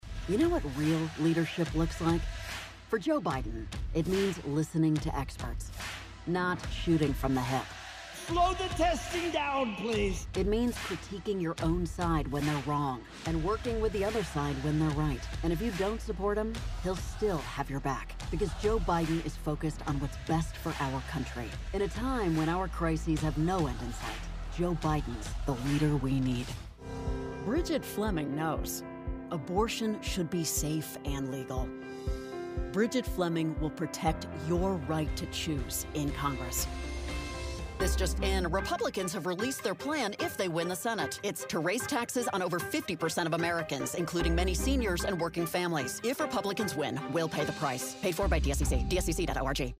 Female
Adult (30-50)
Democratic Political Ads